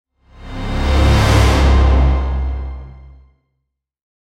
Cinematic Dramatic Haunted Swoosh Transition Sound Effect
Description: Cinematic dramatic haunted swoosh transition sound effect. Intense and suspenseful orchestral rise creates eerie tension with haunting strings and dark atmospheric layers.
Cinematic-dramatic-haunted-swoosh-transition-sound-effect.mp3